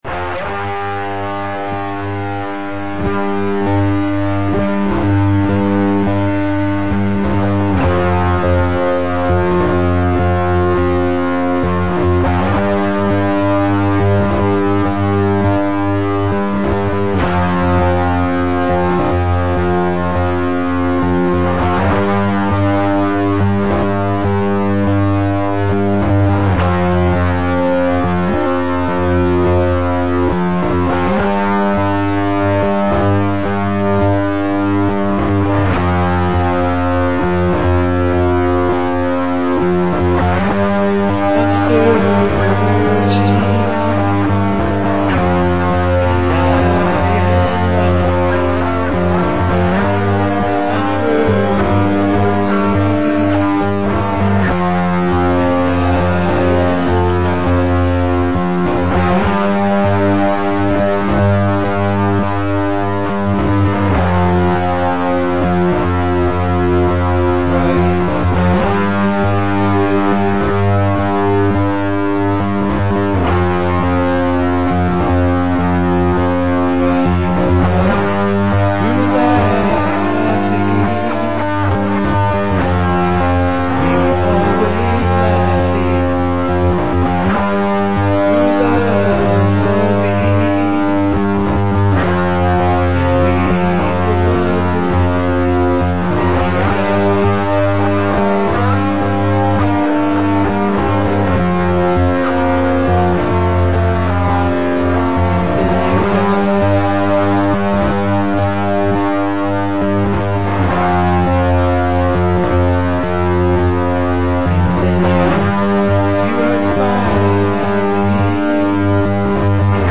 Warning: the melodies of this song are XIan Phonetic!
Native American Heavy Metal Band